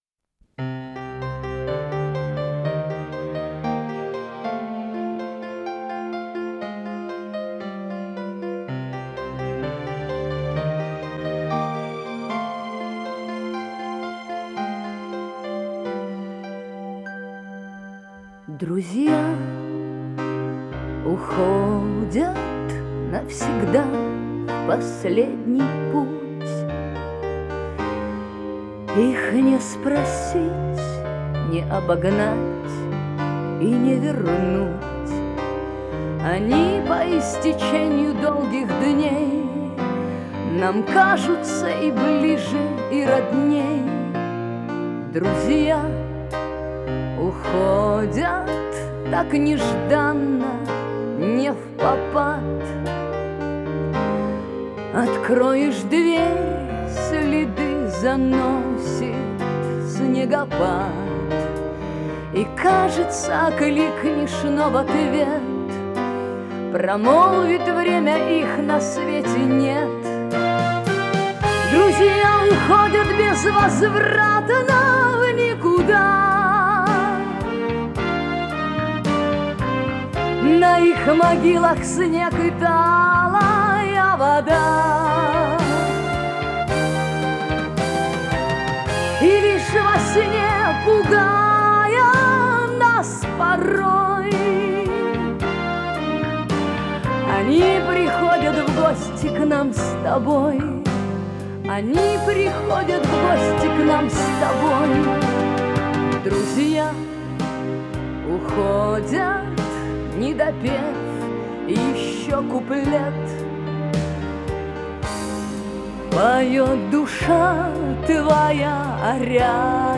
с профессиональной магнитной ленты
Скорость ленты38 см/с